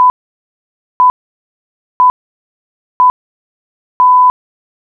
countdown.wav